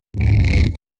うなり声